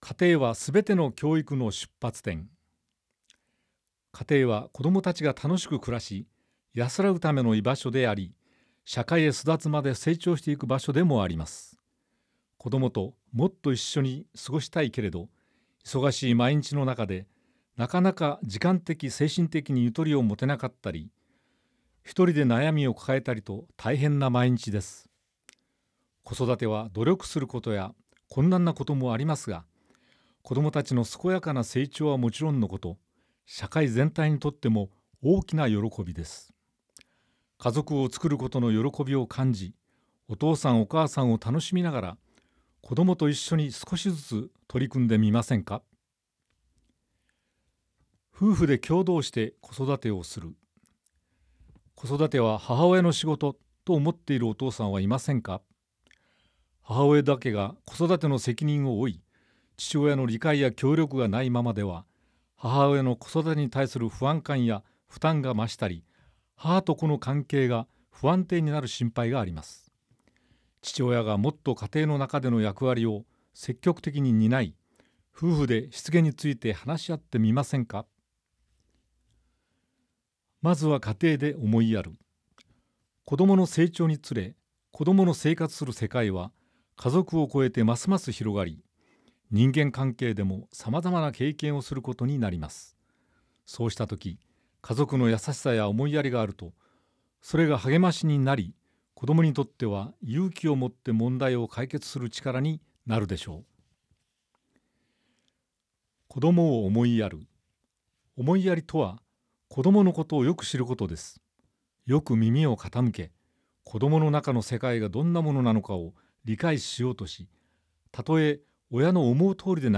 ■朗読ボランティア「やまびこ」が音訳しています